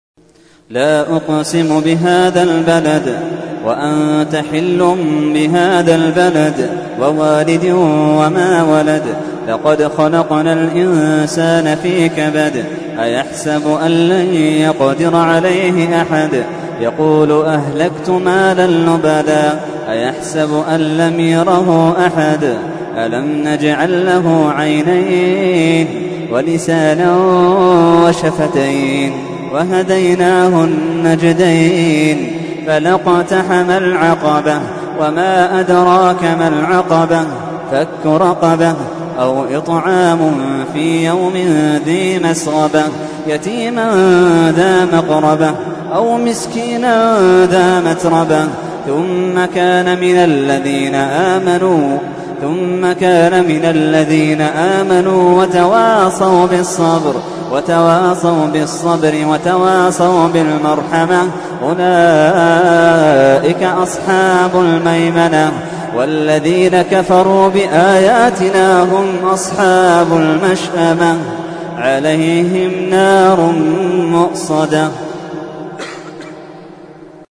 تحميل : 90. سورة البلد / القارئ محمد اللحيدان / القرآن الكريم / موقع يا حسين